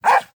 Minecraft Version Minecraft Version snapshot Latest Release | Latest Snapshot snapshot / assets / minecraft / sounds / mob / wolf / puglin / bark3.ogg Compare With Compare With Latest Release | Latest Snapshot
bark3.ogg